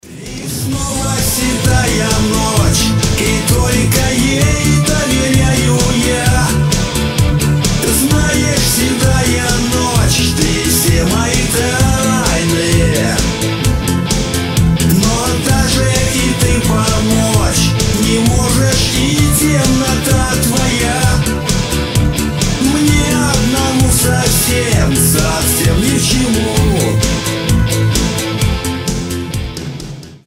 Mashup , Cover
Ретро , Нейросеть